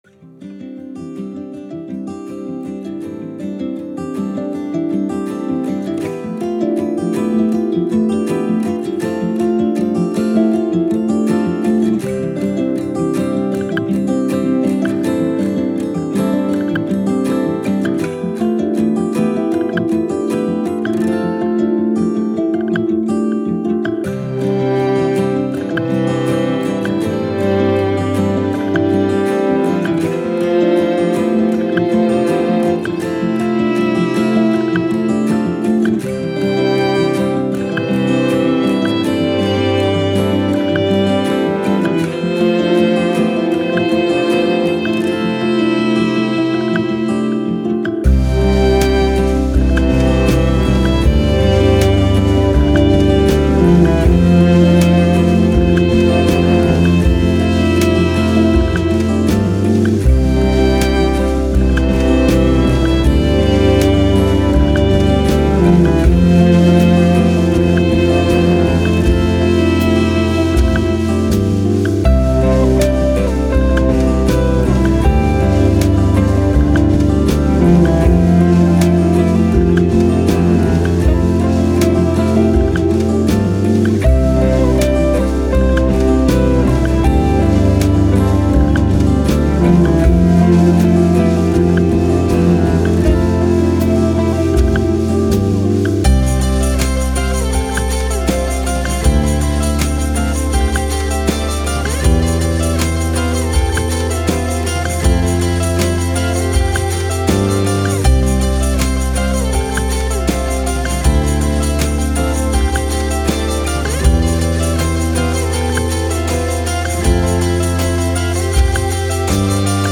Downtempo, Soundtrack, Guitars, Strings, Emotive, Thoughtful